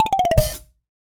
crash.ogg